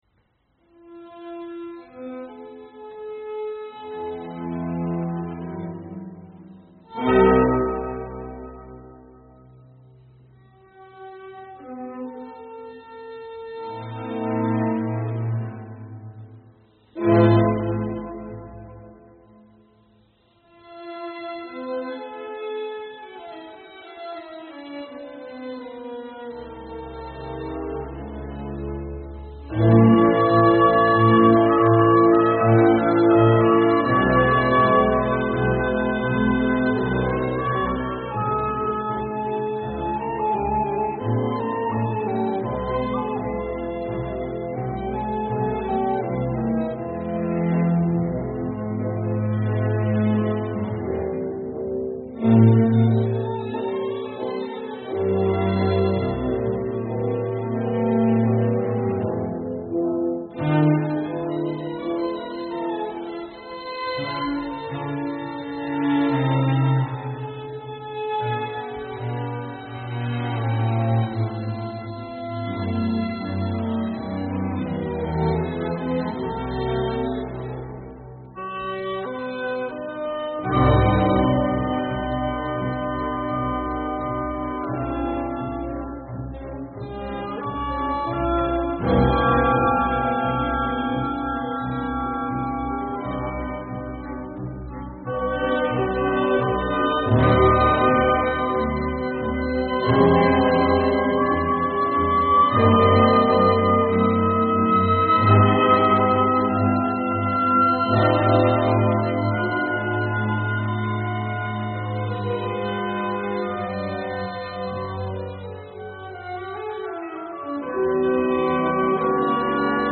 Mass in C major
(Period Instruments)